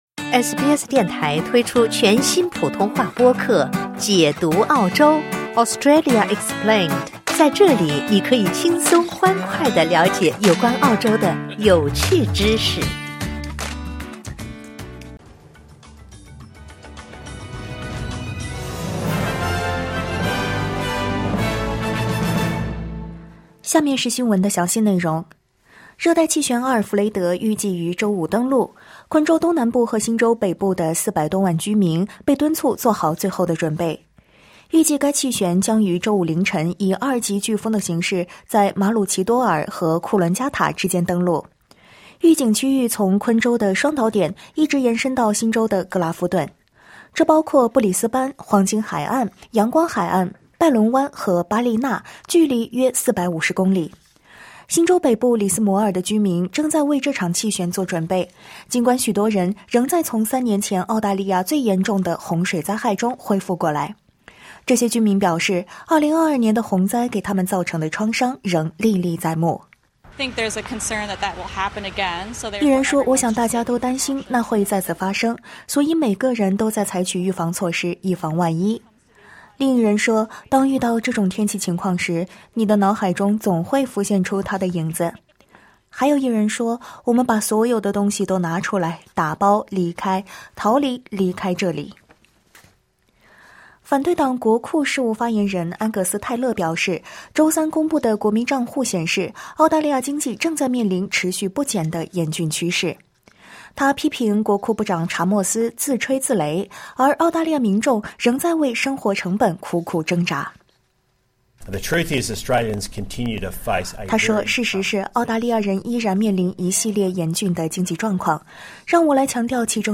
SBS早新闻（2025年3月6日）